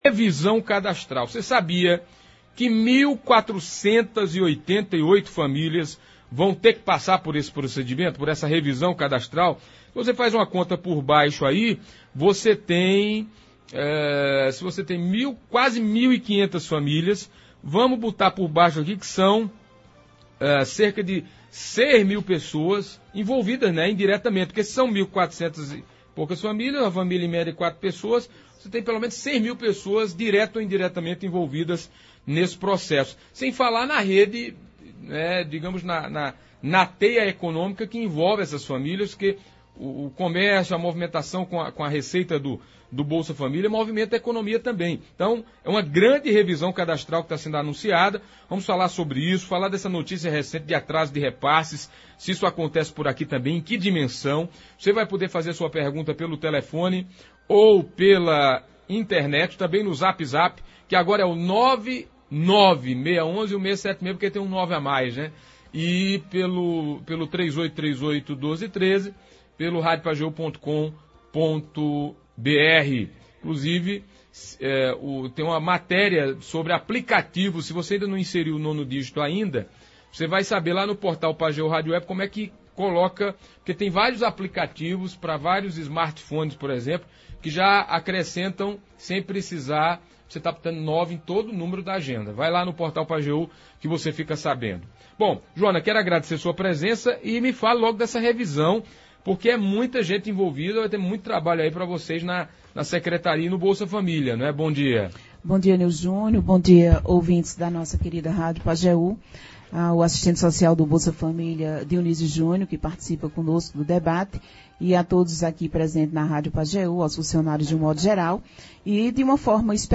Hoje nos estúdios da Pajeú